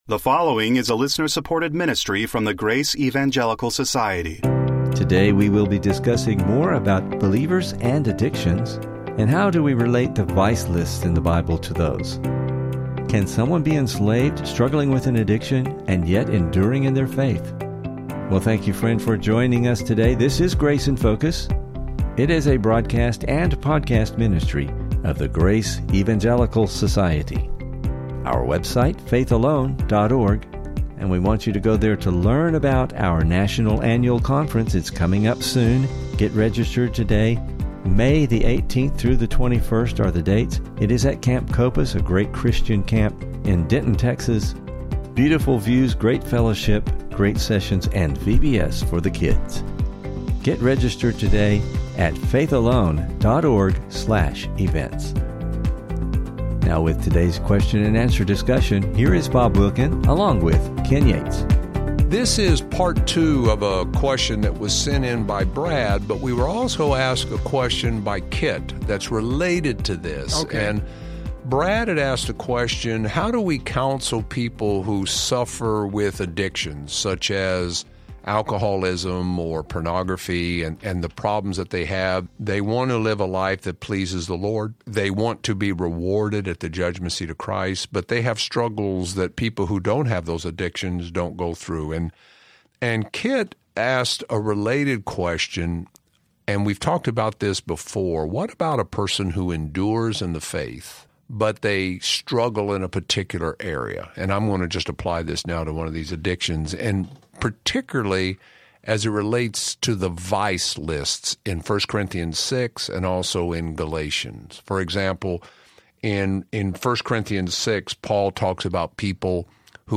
In the Bible’s so called vice lists, there are certain people who are said to not be eligible to inherit the kingdom. What is the difference between being enslaved and with struggling with an addiction, yet enduring in faith? Please listen for an informative discussion and never miss an episode of the Grace in Focus Podcast!